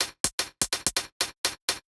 Index of /musicradar/ultimate-hihat-samples/125bpm
UHH_ElectroHatA_125-03.wav